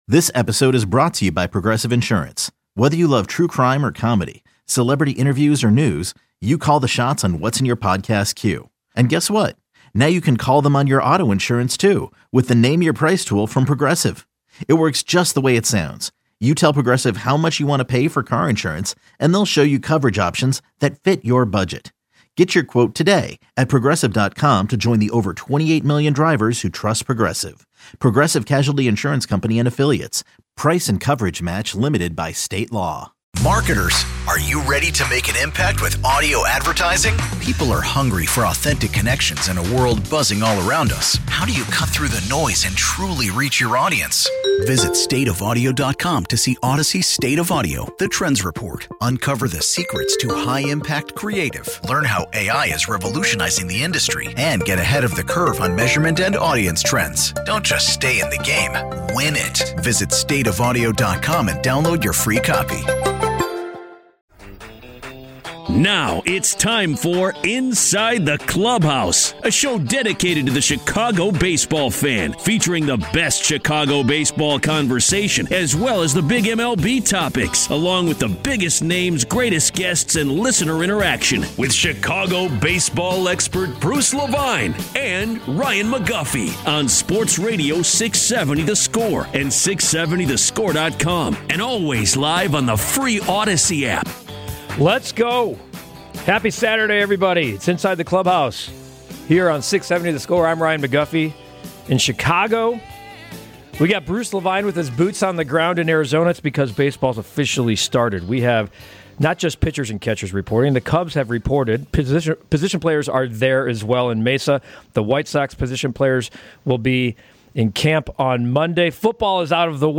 discussing the latest Cubs, White Sox and MLB storylines while also welcoming on great guests from across the baseball landscape.